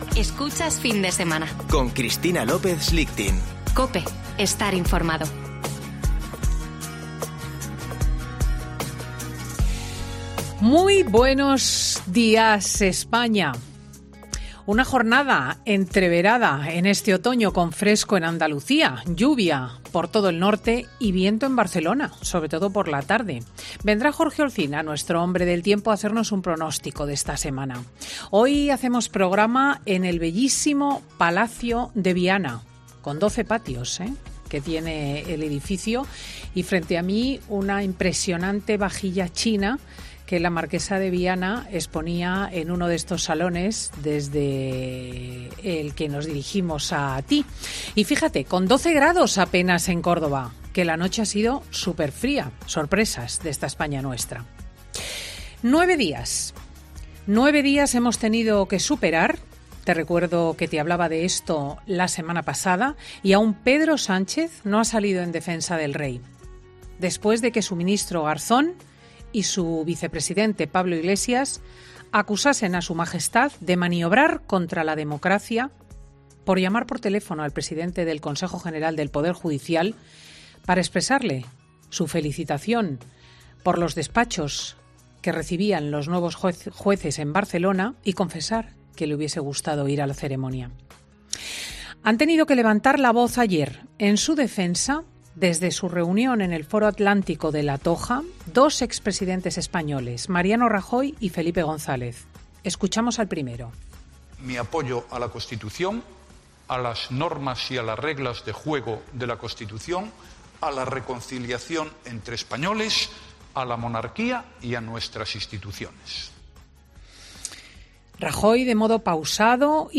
AUDIO: Ya puedes escuchar el monólogo de Cristina López Schlichting de este sábado 3 de octubre de 2020